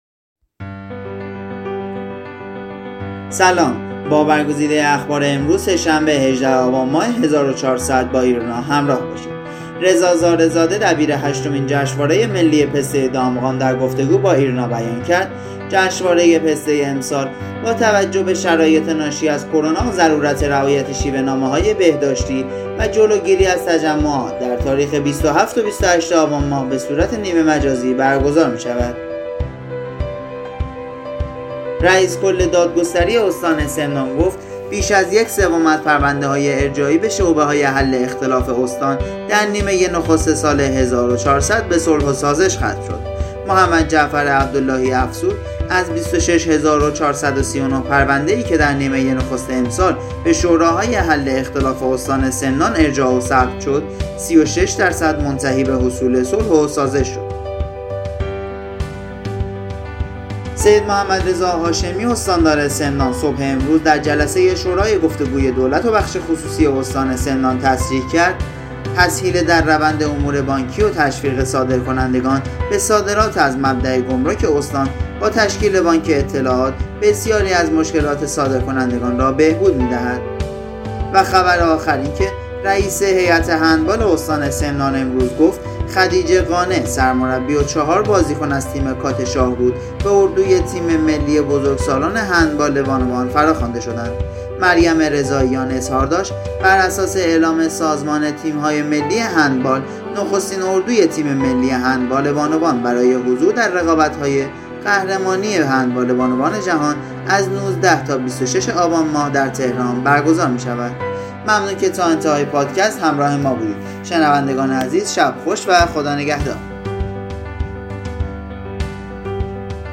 صوت | اخبار شبانگاهی ١٨ آبان استان سمنان